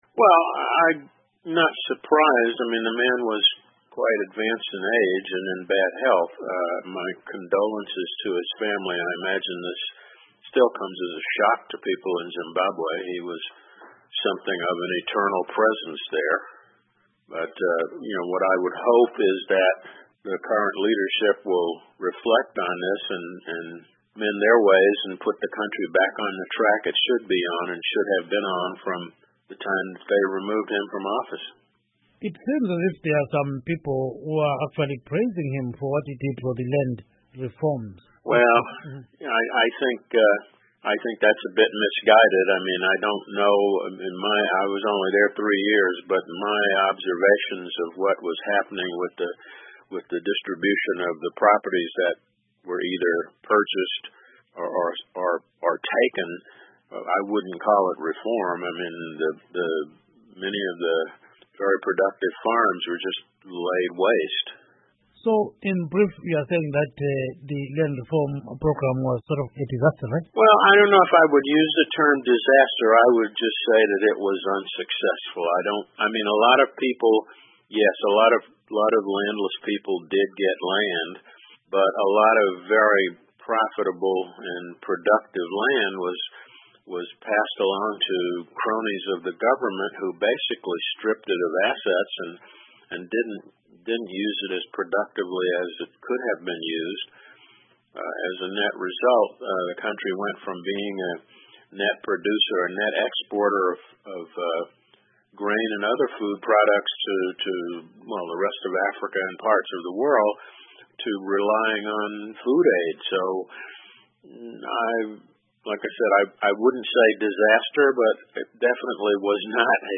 Interview With Former USA Ambassador to Zimbabwe Charles Ray